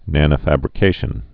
(nănə-făbrĭ-kāshən)